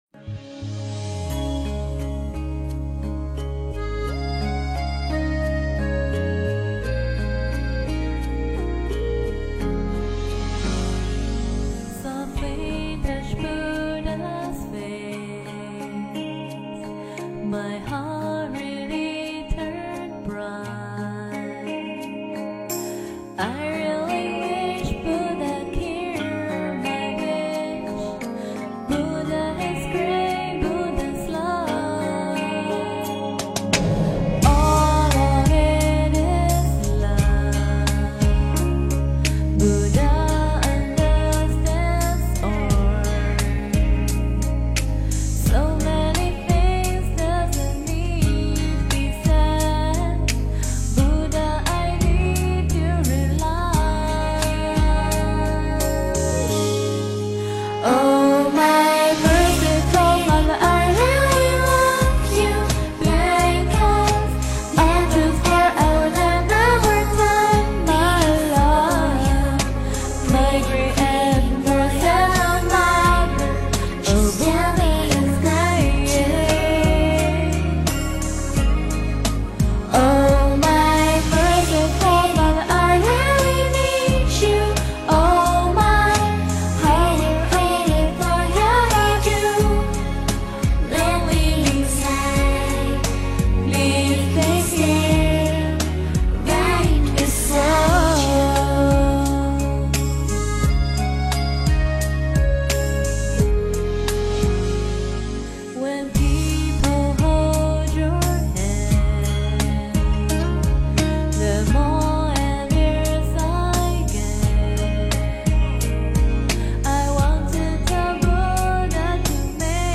Merciful Father 诵经 Merciful Father--佛教音乐 点我： 标签: 佛音 诵经 佛教音乐 返回列表 上一篇： 捉真性 下一篇： 云水逸 相关文章 维摩诘经-菩萨品第四 维摩诘经-菩萨品第四--未知...